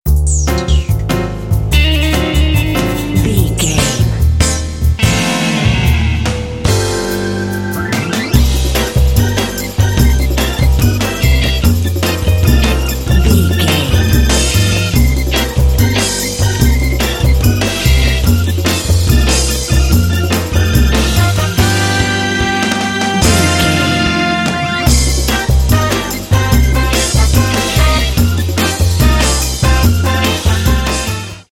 Groovy and fast-paced 12-bar blues phrase.
Uplifting
Aeolian/Minor
Fast
funky
energetic
piano
electric guitar
double bass
electric organ
drums
Funk
blues